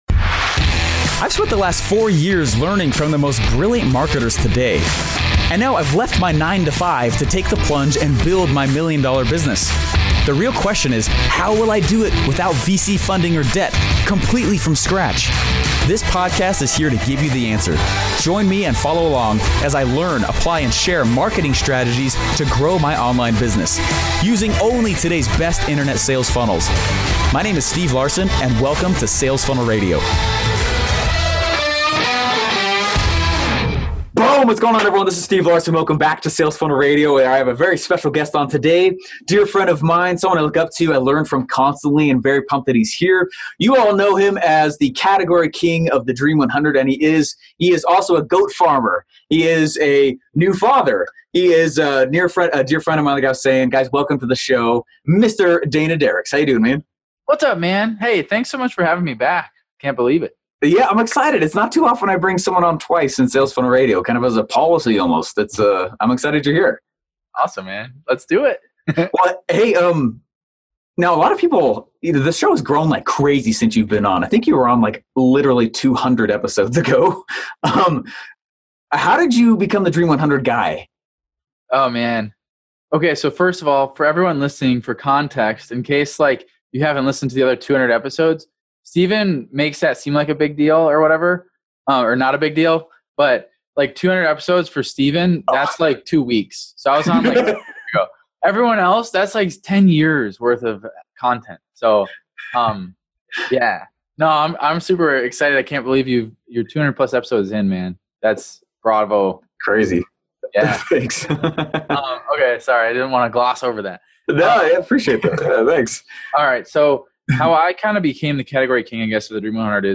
I have a very special guest on today, a dear friend of mine, someone I look up to and learn from constantly…